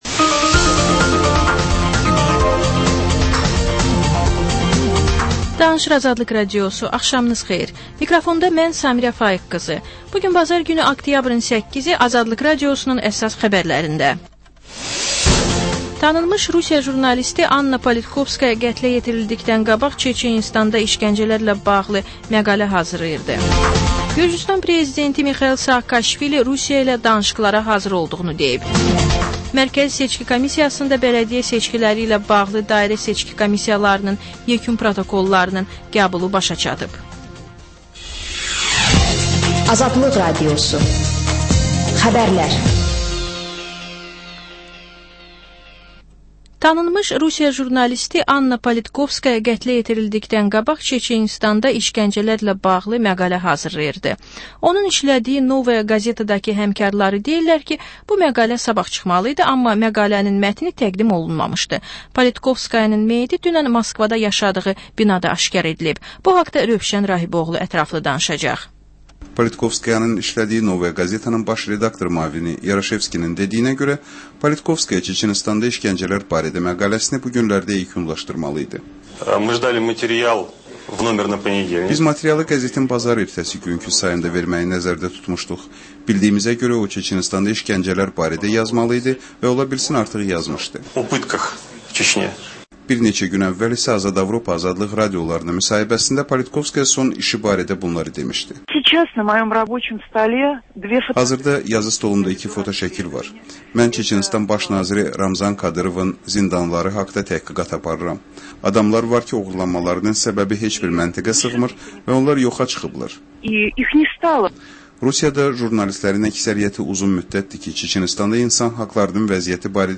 Xəbərlər, reportajlar, müsahibələr.